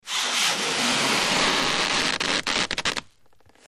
Rubber Stretching And Squeaking, X2